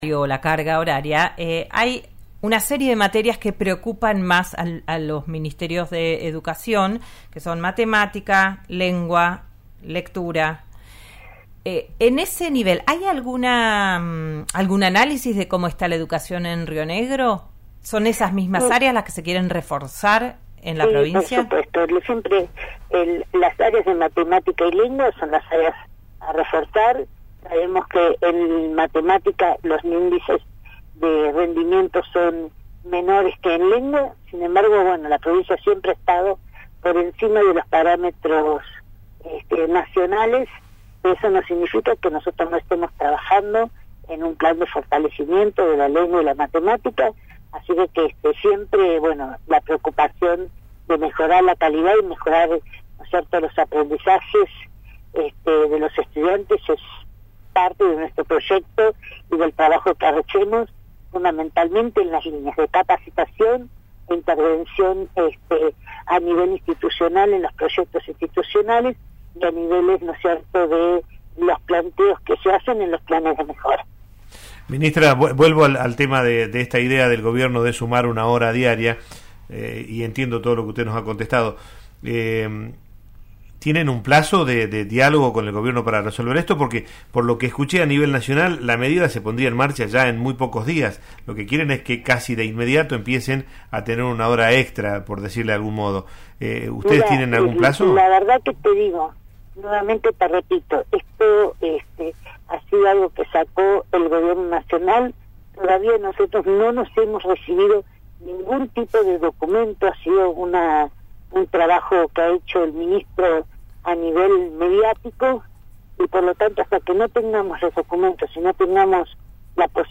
Escuchá la entrevista a Mercedes Jara Tracchia, ministra de Educación de Río Negro, en «Digan lo que digan» por RN RADIO